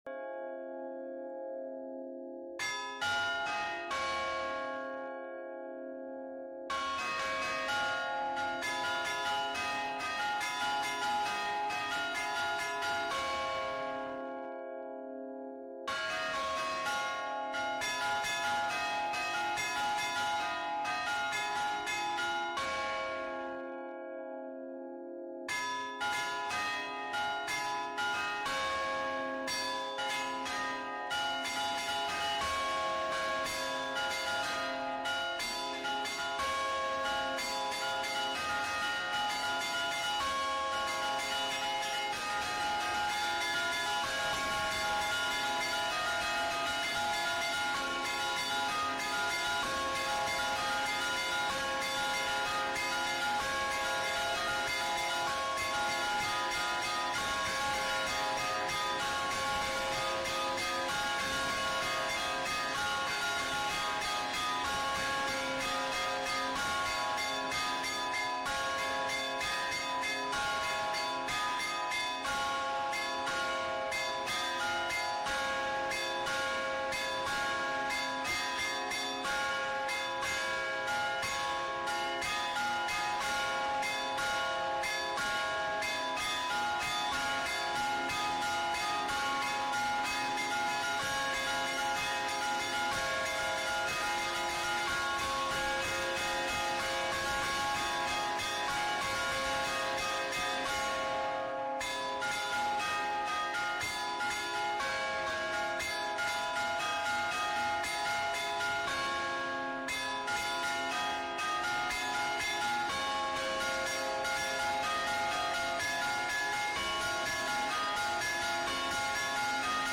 Suonata dei campanari
suonata campanari.mp3